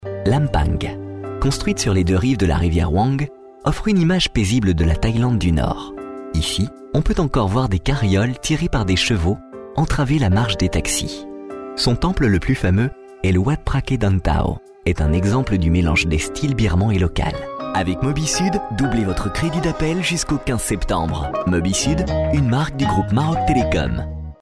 French male voice talent